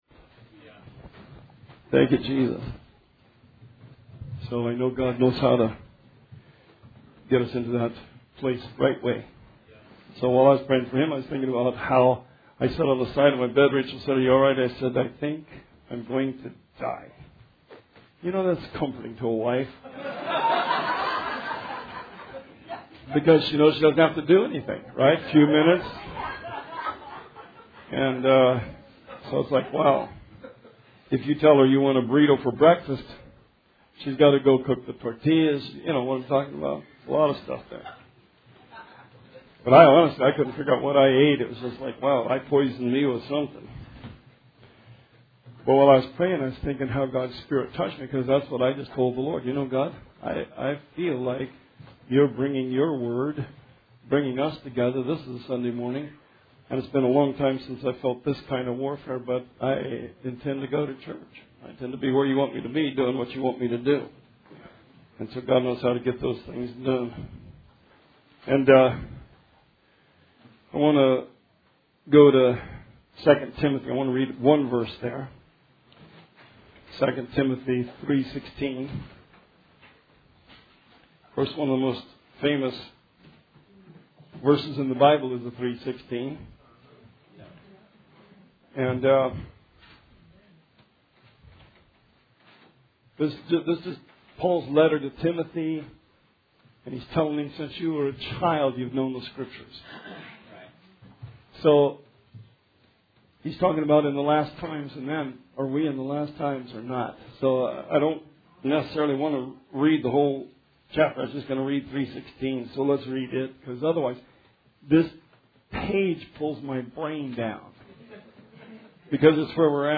Series Sermon